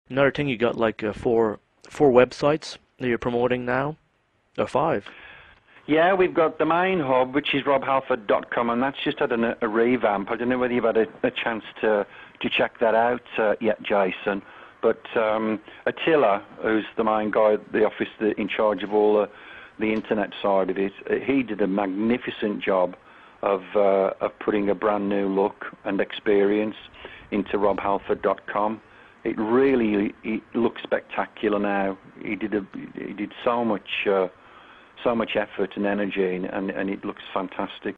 — Rob Halford (lead singer of Judas Priest) in Interview